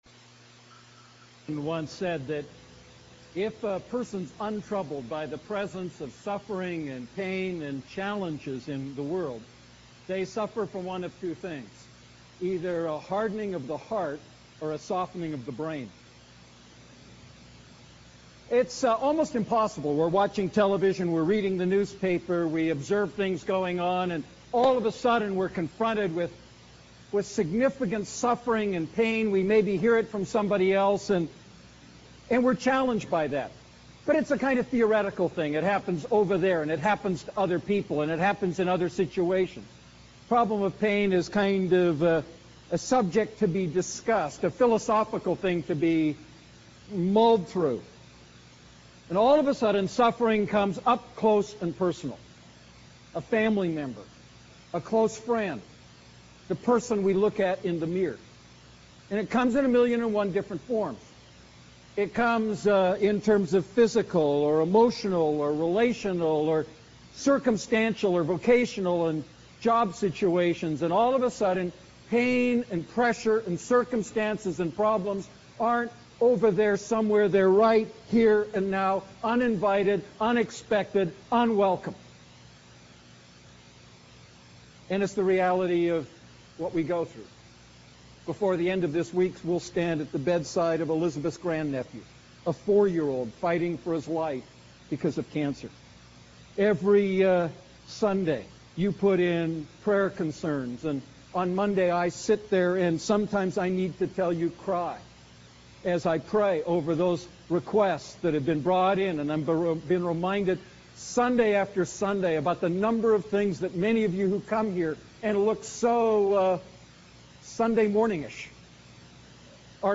A message from the series "Defining Moments."